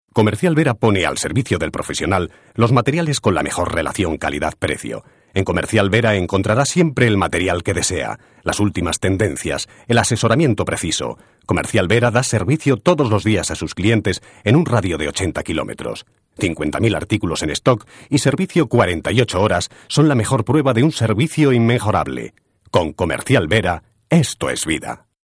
locutor de publicidad. Promoción de vídeo locutor